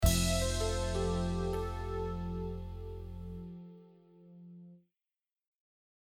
80 Description: Relaxed, latin Mood: Relaxed Instruments
saxophone Genre: Latin Composer/Artist
Plays short end of the track